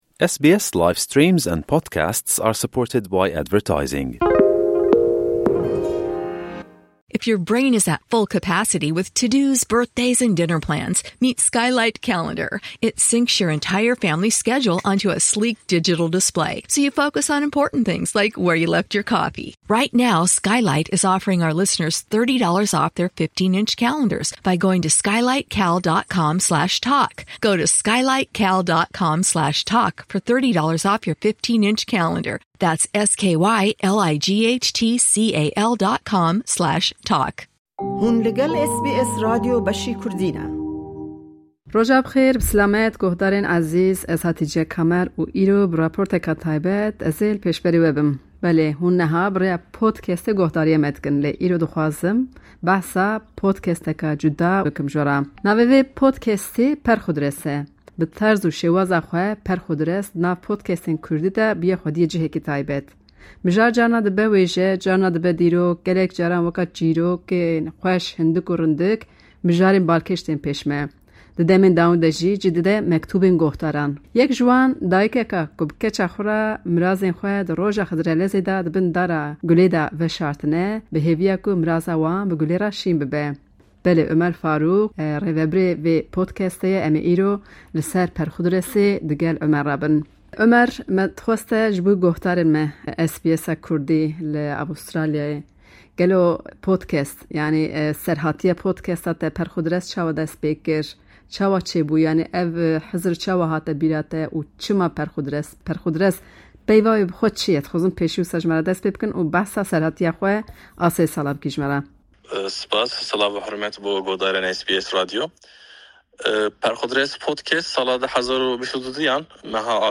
gotûbêjeke taybet